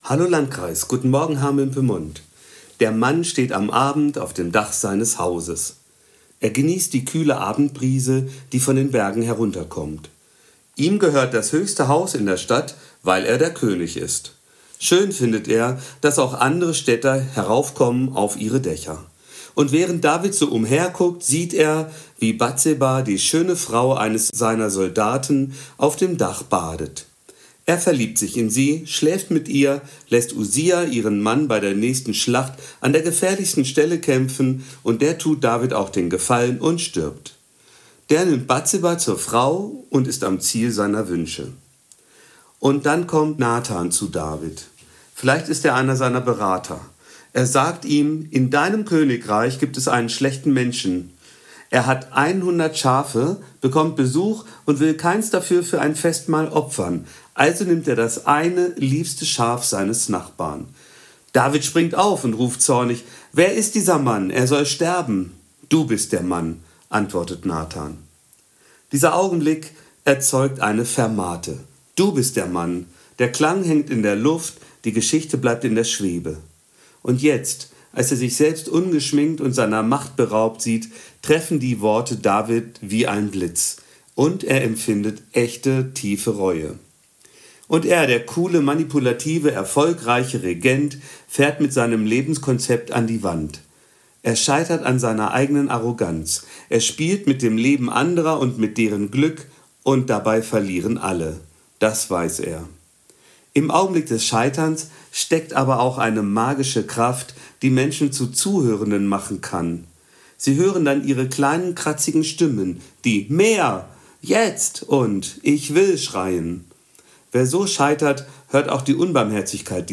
Radioandacht vom 6. Mai